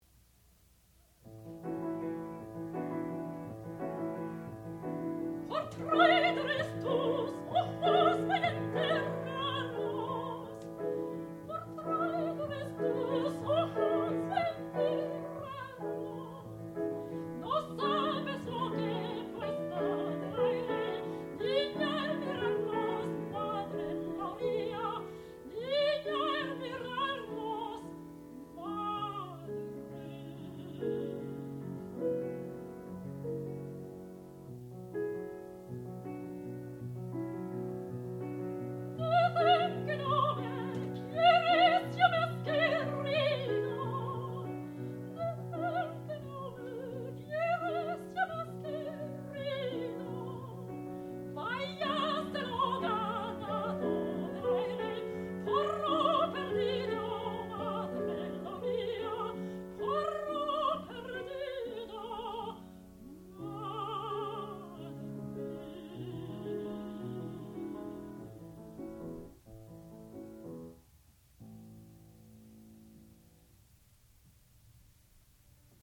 sound recording-musical
classical music
piano
Master's Recital
soprano